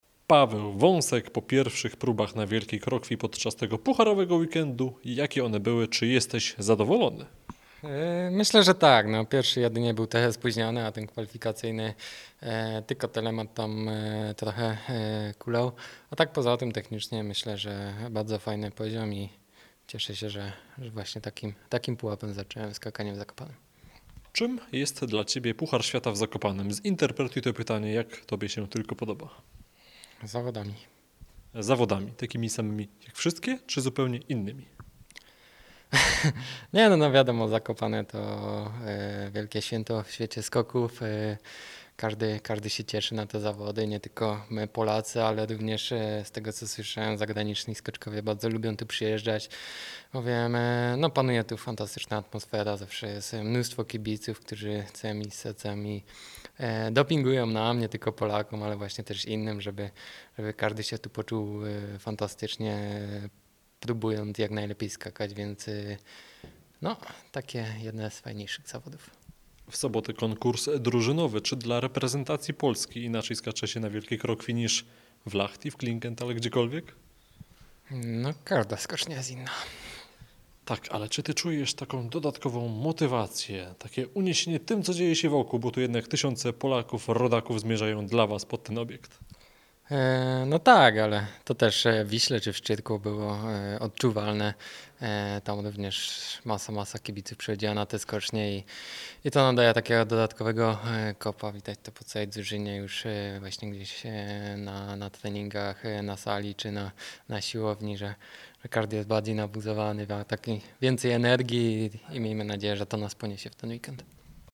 Pawel-Wasek-po-kwalifikacjach-w-Zakopanem.mp3